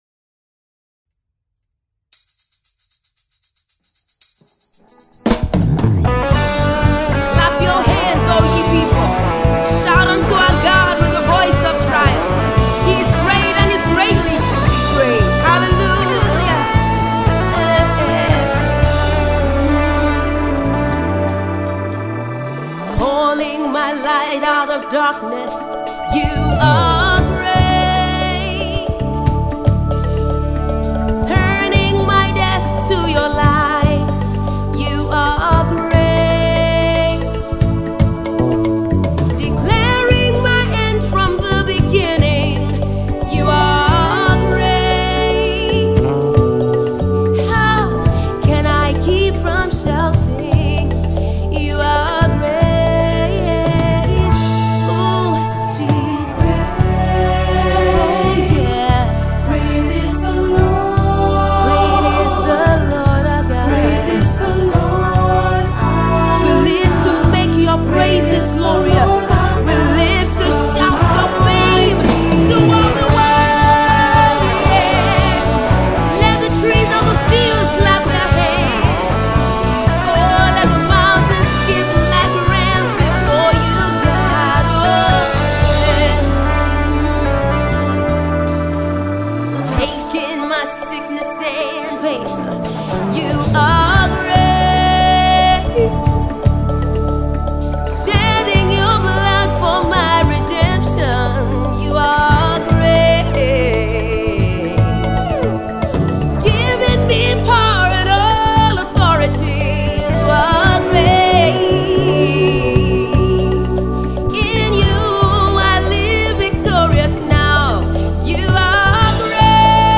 pure worship record